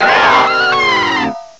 cry_not_dialga.aif